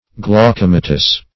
Glaucomatous \Glau*co"ma*tous\, a.
glaucomatous.mp3